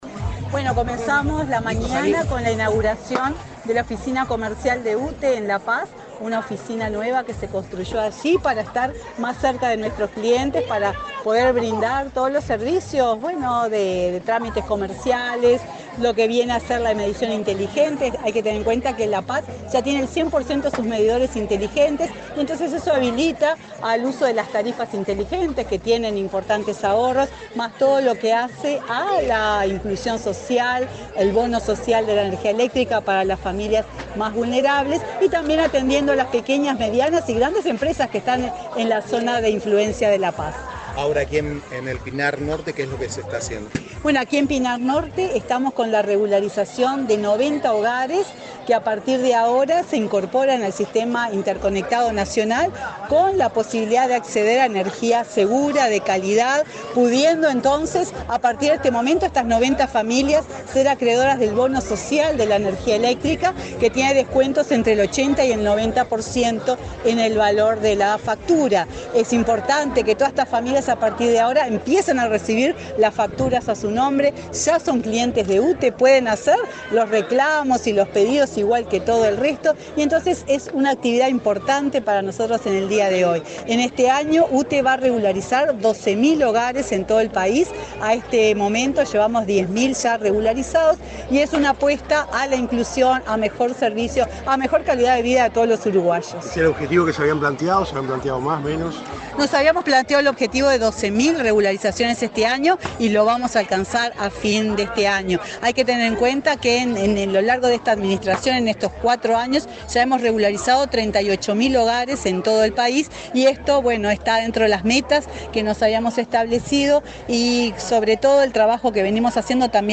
Declaraciones de la presidenta de UTE, Silvia Emaldi
Declaraciones de la presidenta de UTE, Silvia Emaldi 04/10/2024 Compartir Facebook X Copiar enlace WhatsApp LinkedIn La presidenta de UTE, Silvia Emaldi, dialogó con la prensa en Canelones, durante una recorrida que realizó por ese departamento, donde inauguró una oficina comercial en La Paz y obras de electrificación rural en el barrio Pinar Norte.